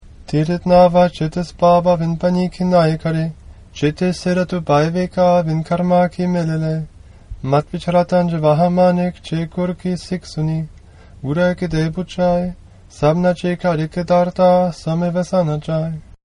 Die Tondateien mit den einzelnen Pauris des Japji Sahib dienen dem Erlernen der Aussprache.